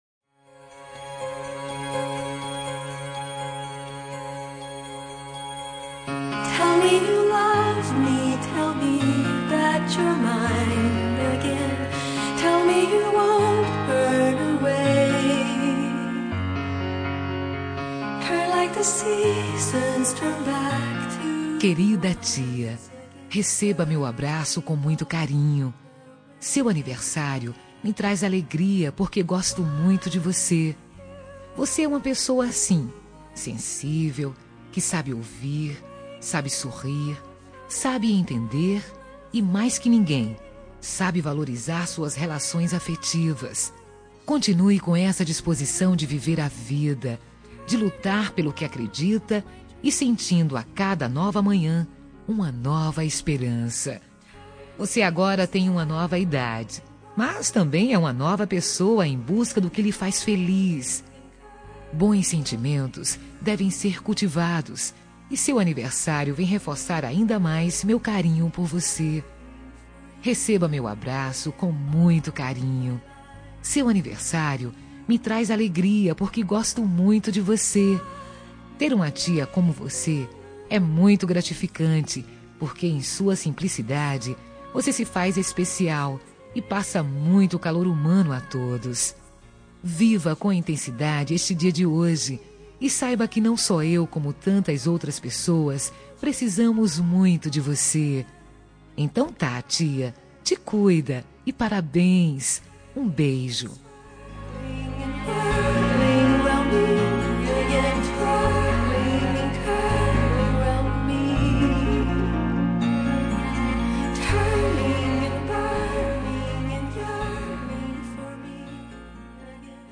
Telemensagem Aniversário de Tia – Voz Feminina – Cód: 1994